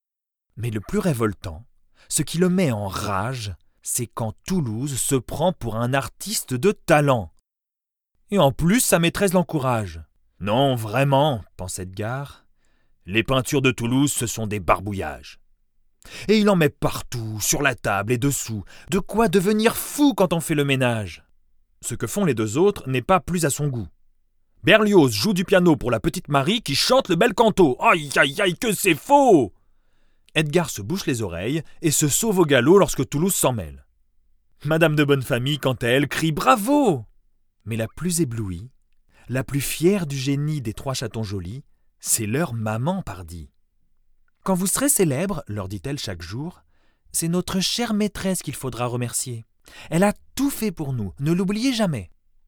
Narration enfant
Les Aristochats : voix medium naturelle